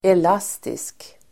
Uttal: [el'as:tisk]